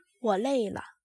ウォ レイ ラ